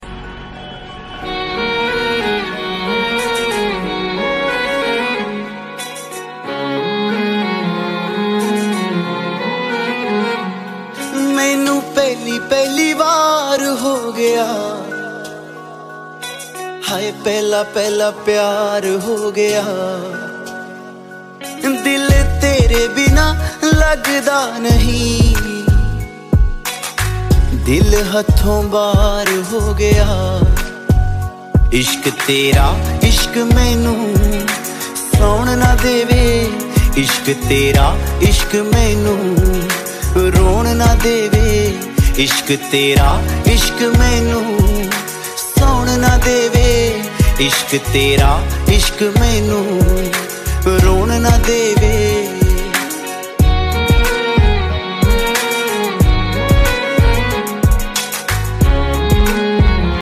Categories Punjabi Ringtones